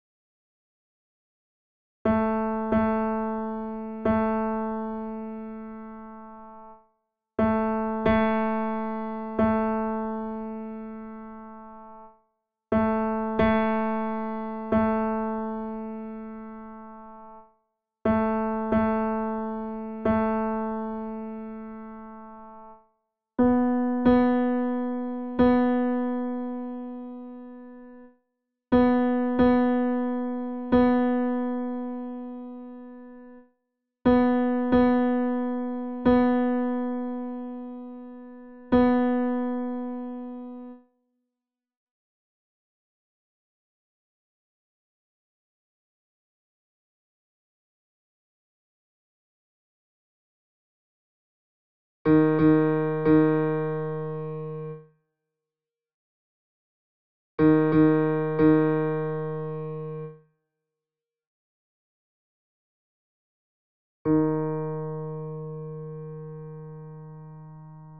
Ensemble - Ténor Seul - Chorale Concordia 1850 Saverne
Ensemble-Ténor-Seul.mp3